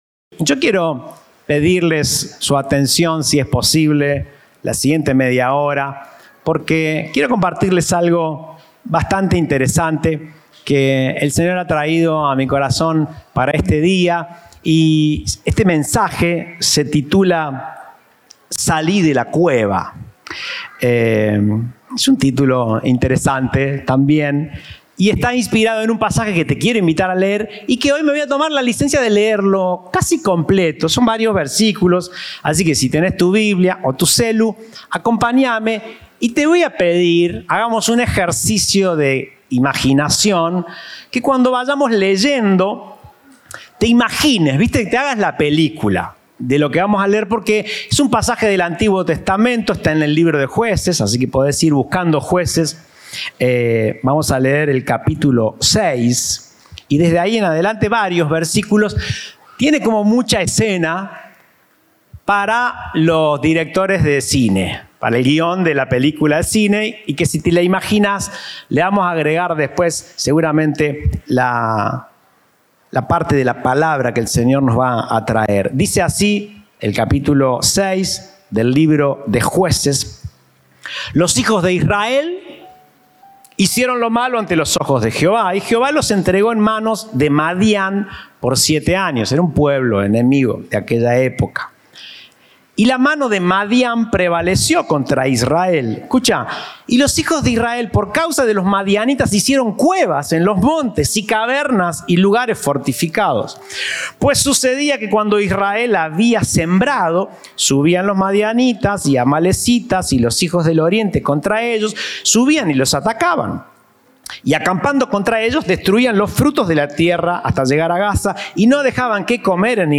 Compartimos el mensaje del Domingo 25 de Febrero de 2024